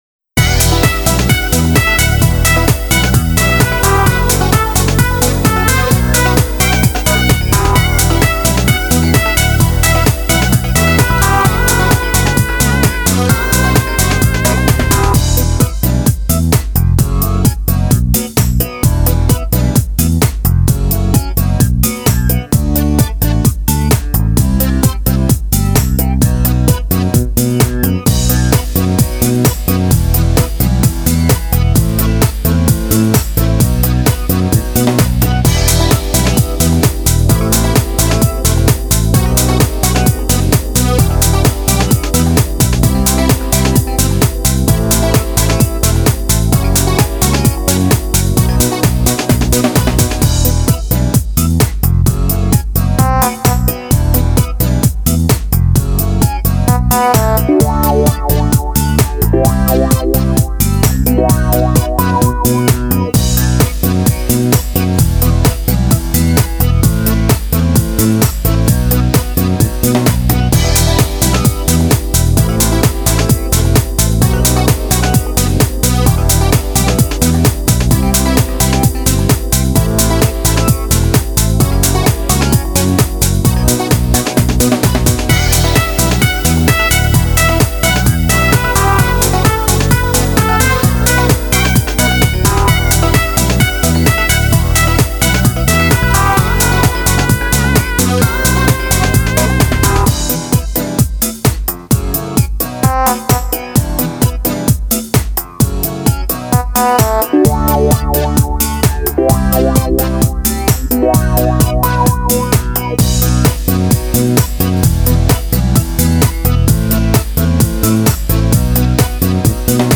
(минусовка)